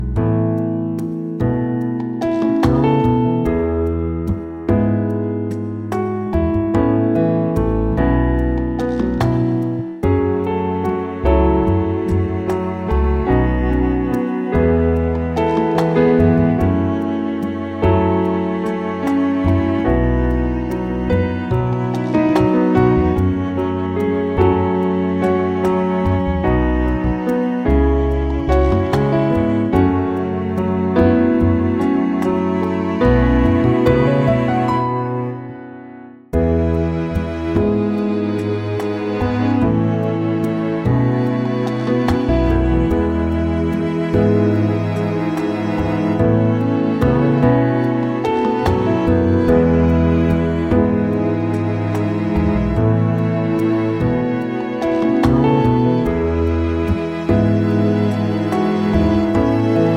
Up 2 Semitones For Female